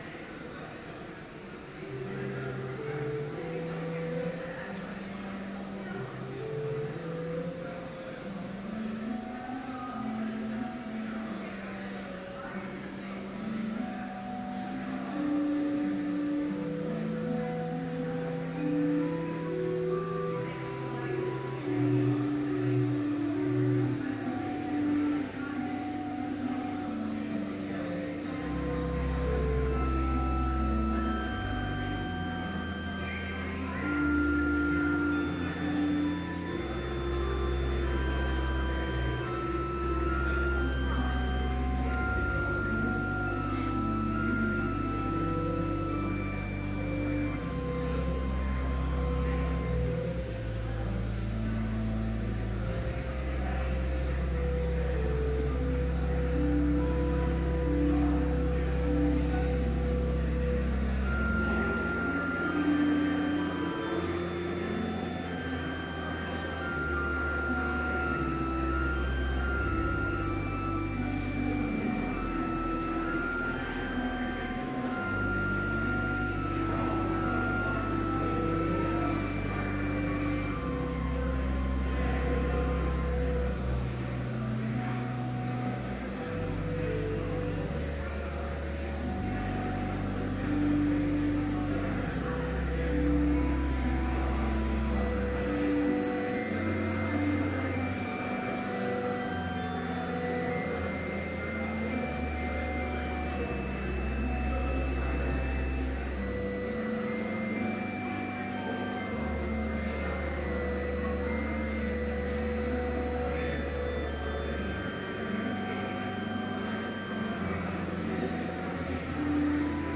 Complete service audio for Chapel - April 3, 2013